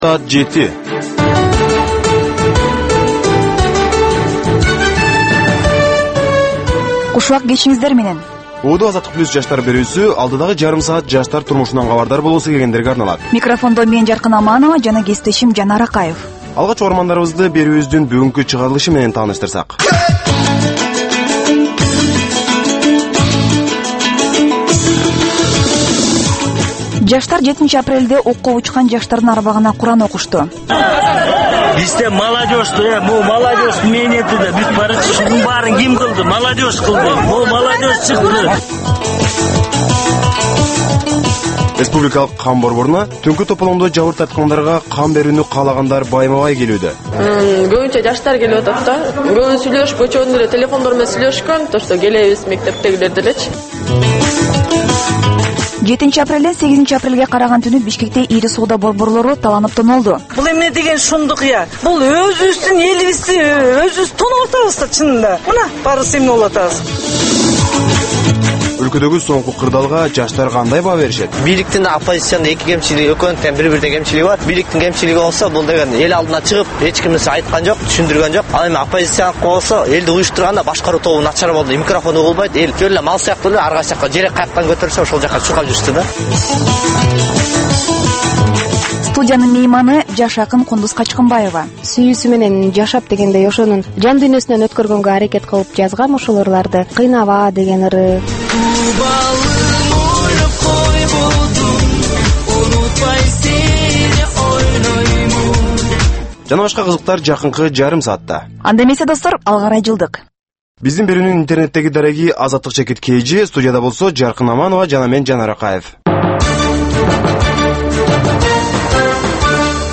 "Азаттык үналгысынын" кыргызстандык жаштарга арналган бул кечки алгачкы үналгы берүүсү жергиликтүү жана эл аралык кабарлардан, репортаж, маек, баян жана башка берүүлөрдөн турат.